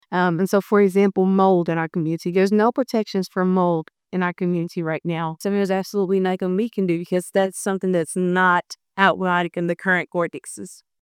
The Human Rights Commission of Hopkinsville–Christian County hosted its second public forum on the Uniform Residential Landlord–Tenant Act.